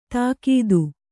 ♪ tākīdu